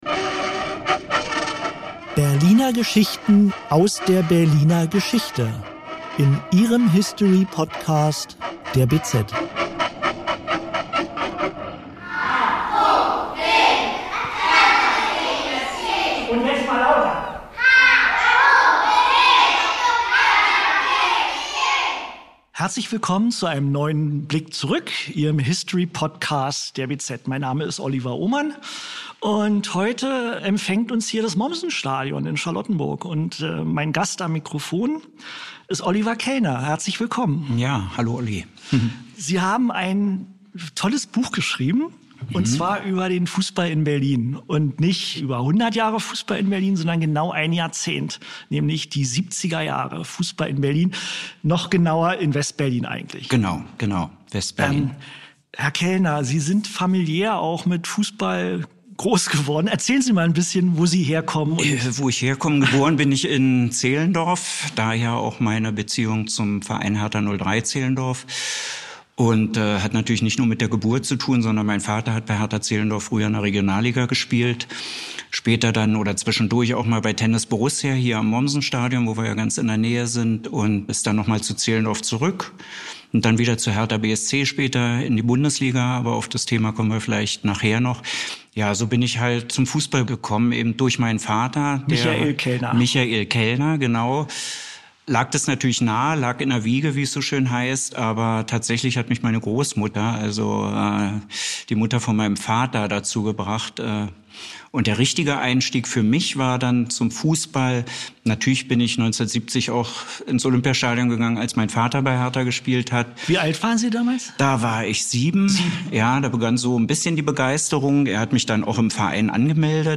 hat einen Experten am Mikro zu Gast